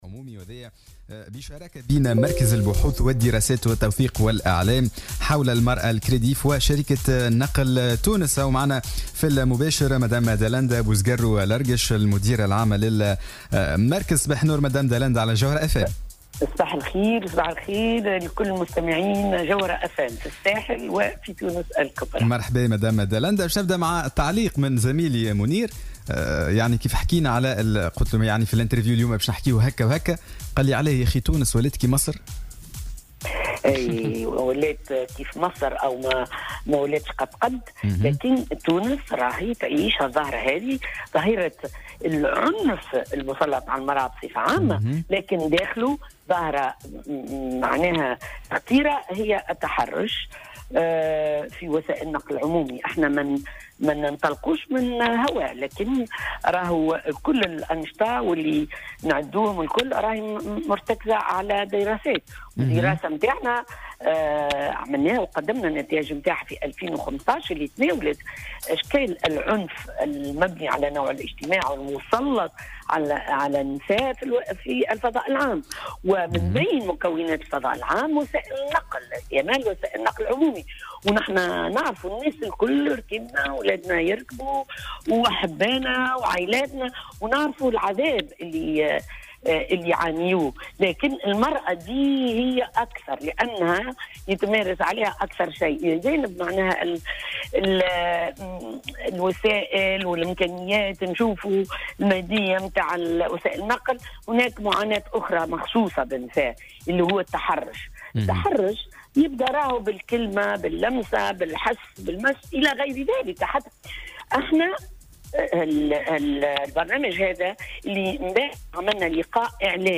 Lors de son intervention dans l'émission Sbéh el Ward ce mercredi 20 septembre 2017